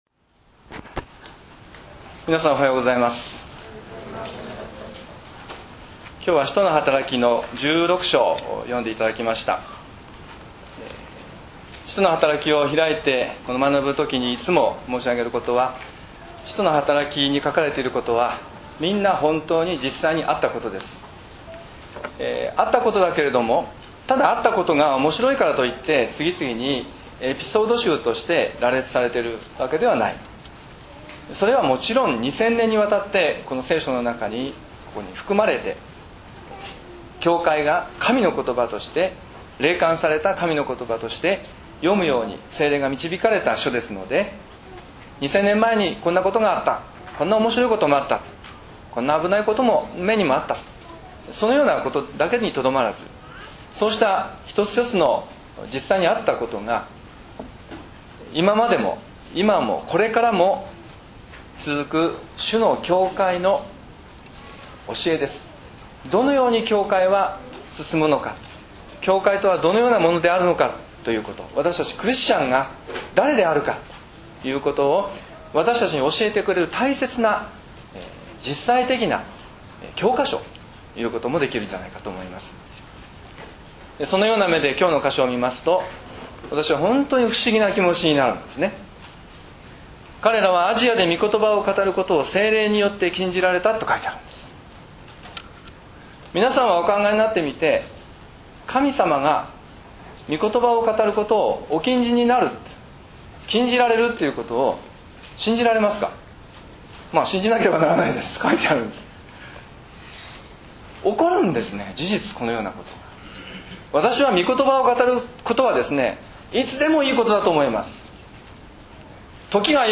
礼拝宣教録音 – 開かれる世界